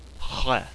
CH - unvoiced uvular fricative